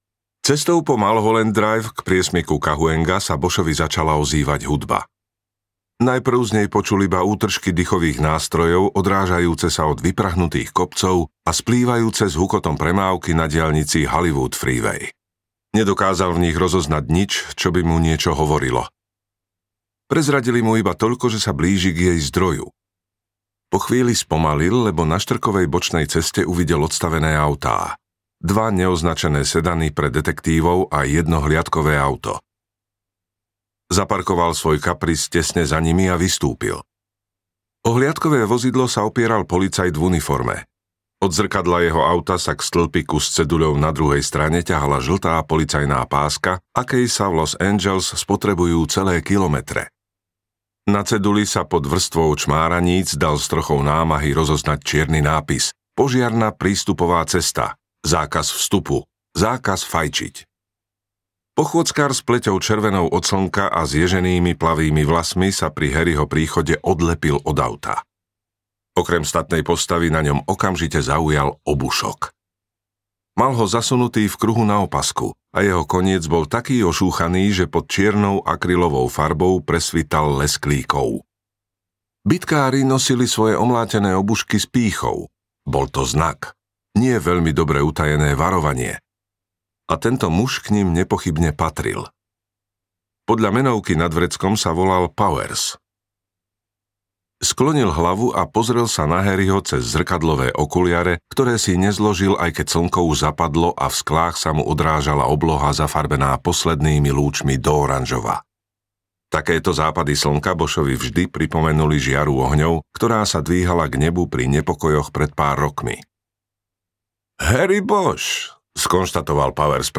Kufrové tango audiokniha
Ukázka z knihy